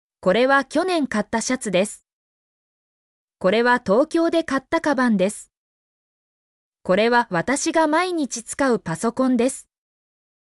mp3-output-ttsfreedotcom-16_pPISwQvA.mp3